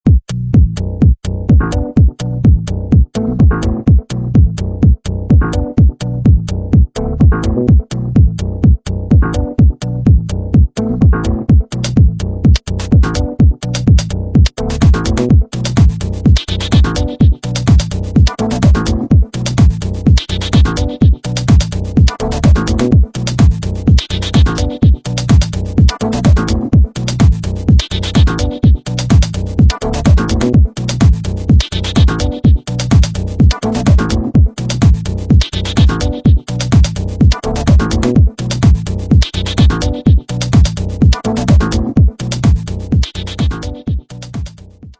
jingle nervous fast instr.